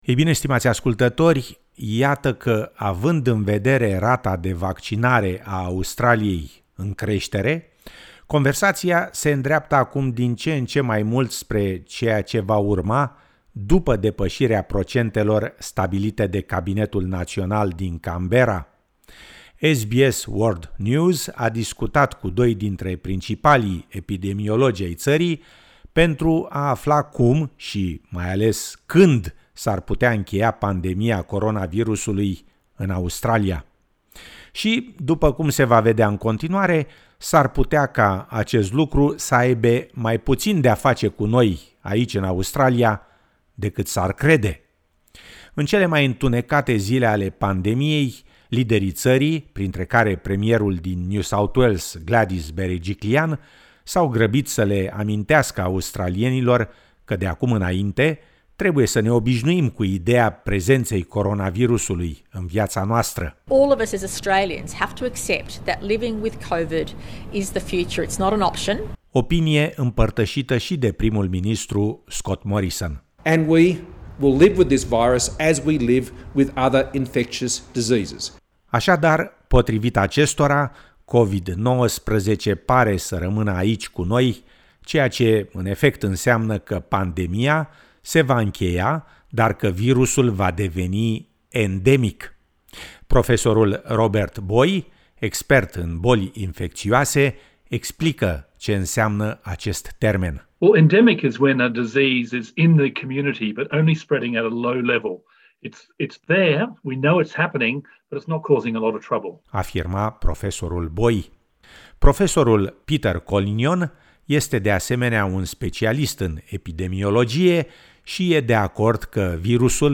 Având în vedere rata de vaccinare a Australiei în creștere, conversația se îndreaptă acum din ce in ce mai mult spre ceea ce va urma dupa depasirea procentelor stabilite de Cabinetul national din Canberra. SBS World News a discutat cu doi dintre principalii epidemiologi ai țării pentru a afla cum și mai ales, când s-ar putea incheia pandemia coronavirusului in Australia.